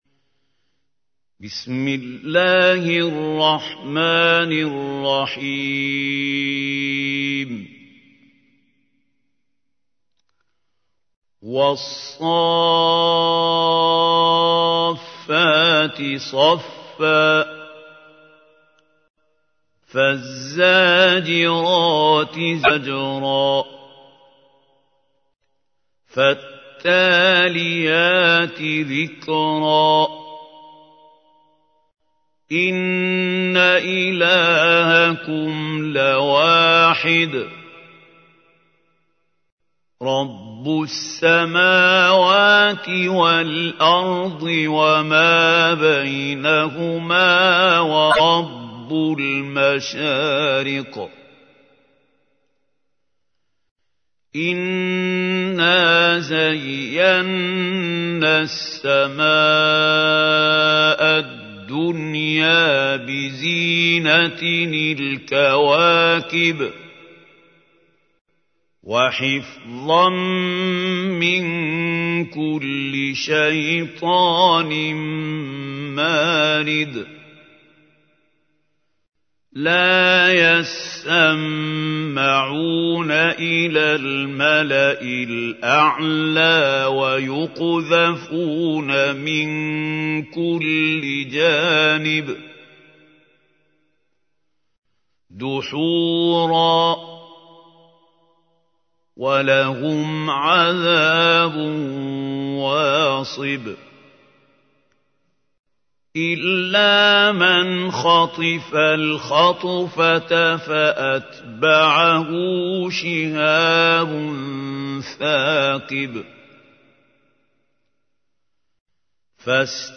تحميل : 37. سورة الصافات / القارئ محمود خليل الحصري / القرآن الكريم / موقع يا حسين